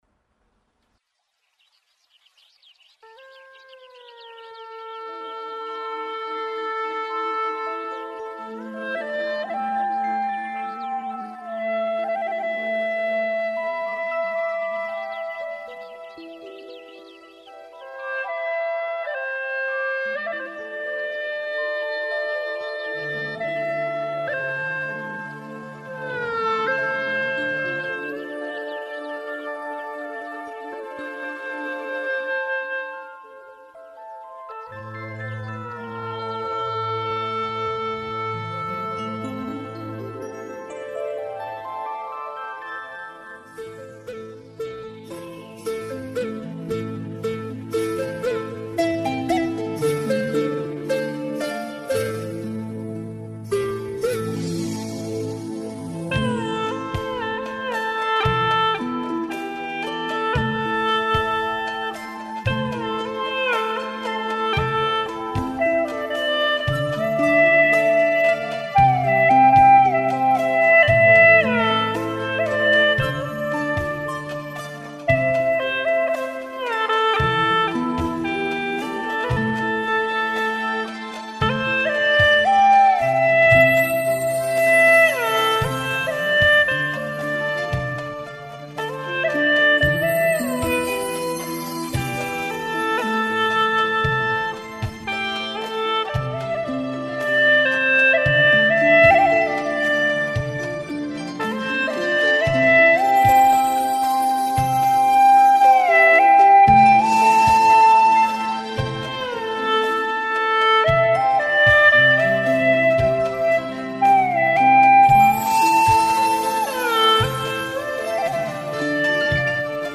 调式 : C